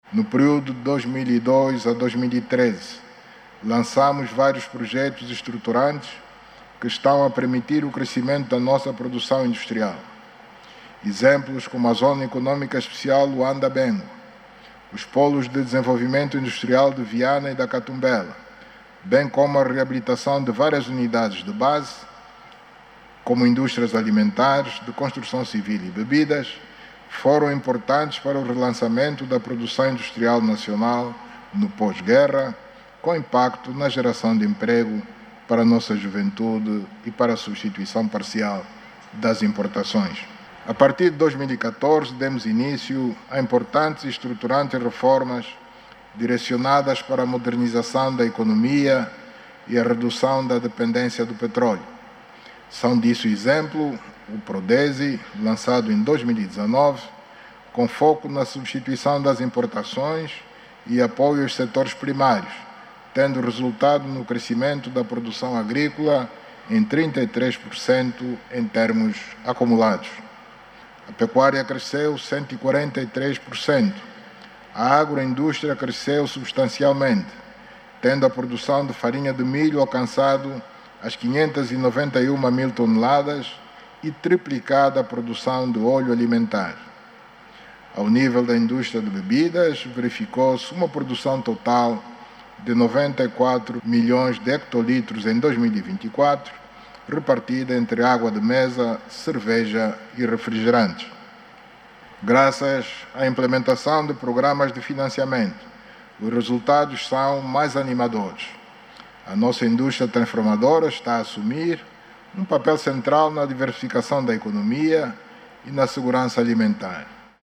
O Presidente da República, João Lourenço, durante a sua mensagem sobre o Estado da Nação proferida ontem, quarta-feira(15), disse está a ser dada uma atenção especial a indústria transformadora que agora assume um papel central na diversificação da economia e na segurança alimentar. O Presidente João Lourenço, disse que as reformas estruturais que estão em curso  desde 2014 no sector da indústria estão a proporcionar resultados animadores.